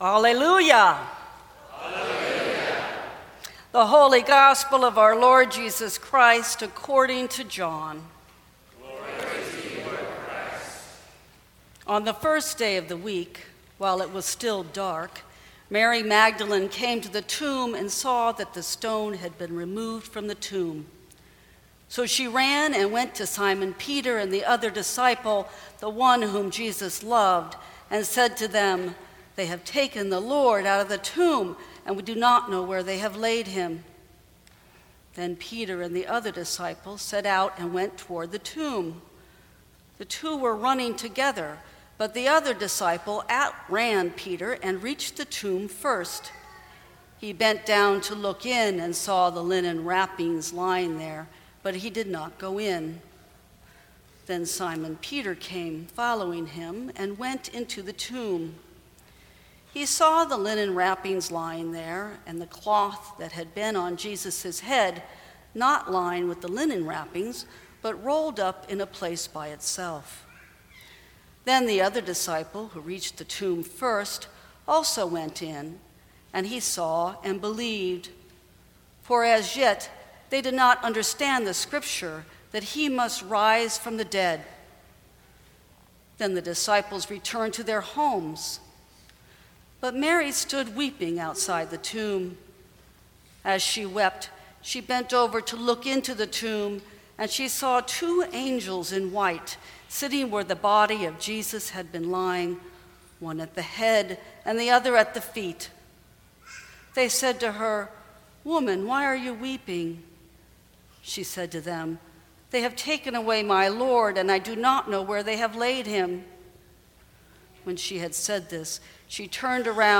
Sermons from St. Cross Episcopal Church Go and Tell Apr 16 2017 | 00:17:45 1x 00:00 / 00:17:45 Subscribe Share Apple Podcasts Spotify Overcast RSS Feed Share Link Embed